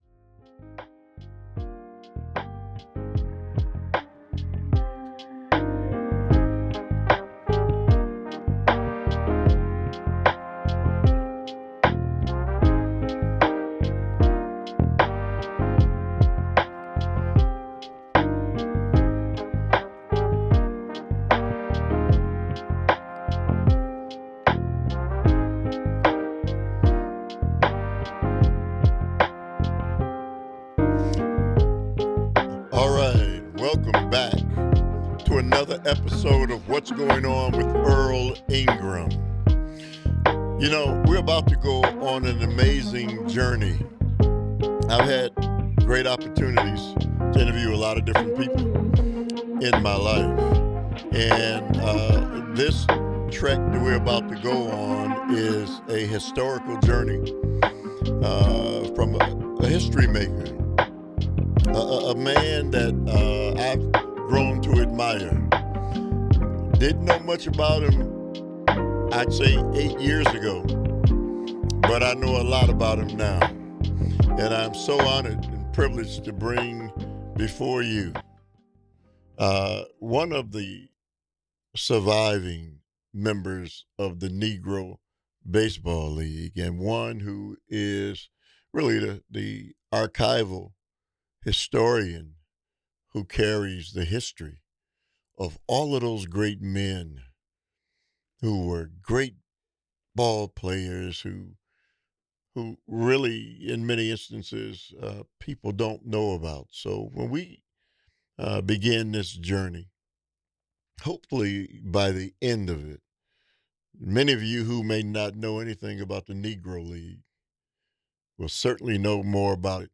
He reflects on a lifetime of breaking barriers, preserving Black baseball history, and sharing wisdom with the next generation. Don’t miss this powerful conversation with a living legend.